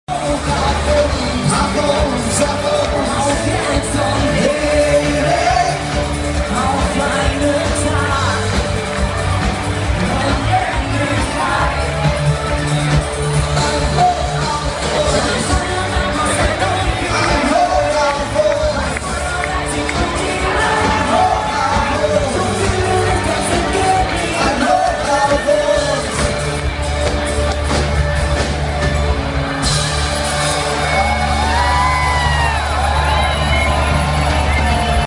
描述：一名德国男子在巴塞罗那的街道上唱歌
标签： 巴塞罗那 唱歌 德国
声道立体声